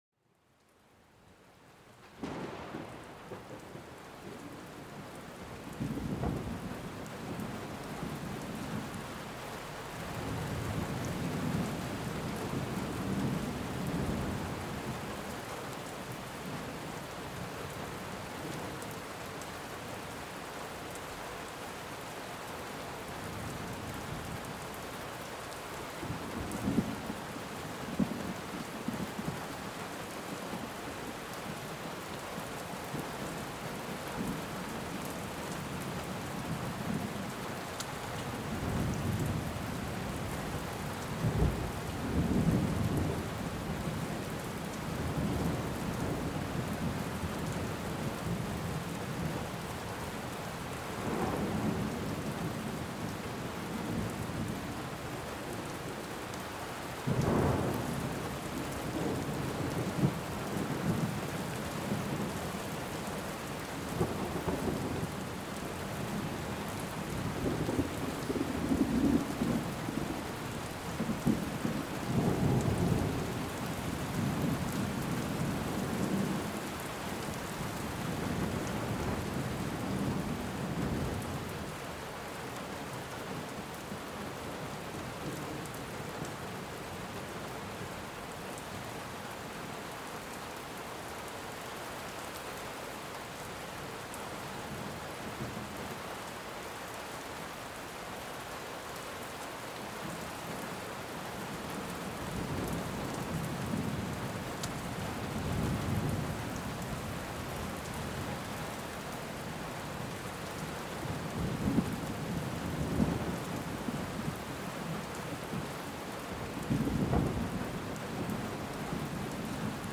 L'orage et la pluie : apaisement et relâchement des tensions
Laissez-vous envelopper par la mélodie de l'orage et le doux crépitement de la pluie. Ces sons puissants et apaisants créent une ambiance réconfortante, idéale pour libérer votre esprit des tensions de la journée.